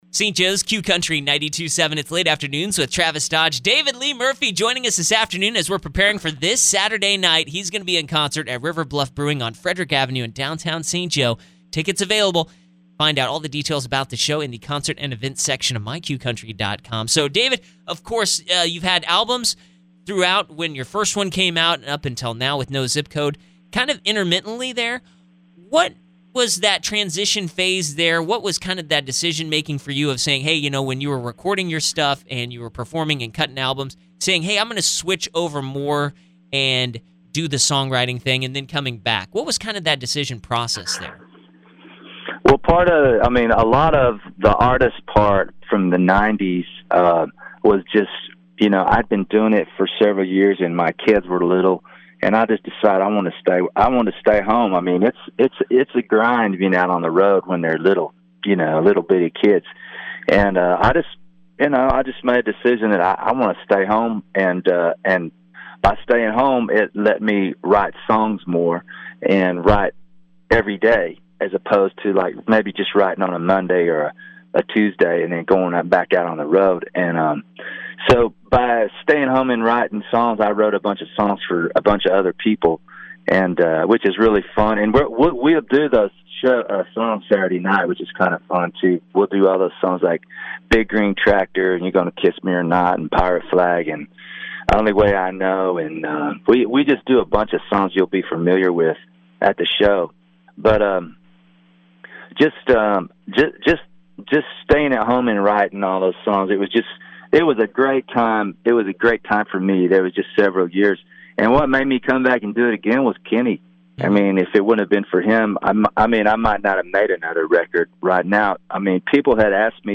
David Lee Murphy Interview Leading Up To His Show At River Bluff Brewing